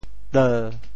潮语发音
teu1.mp3